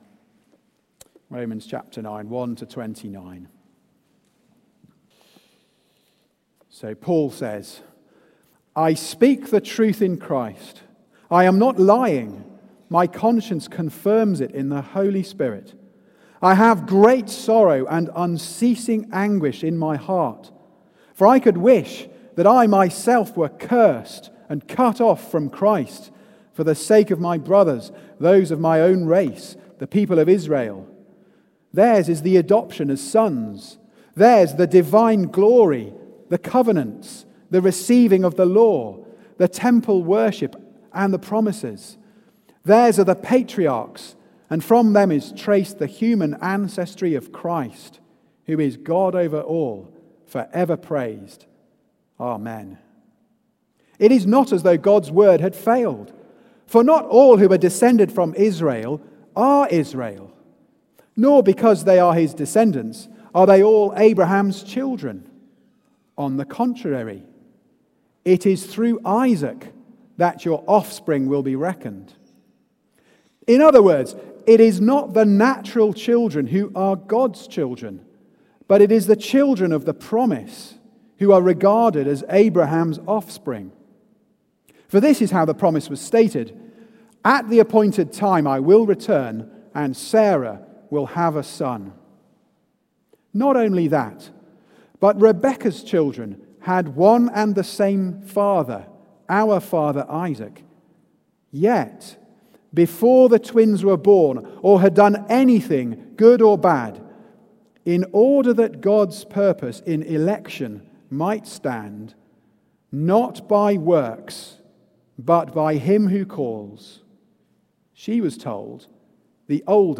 Series: God's Gospel Goal: Romans 9-11 Theme: It Depends on the Mercy of God Sermon